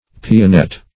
Pianette \Pi*a*nette"\
pianette.mp3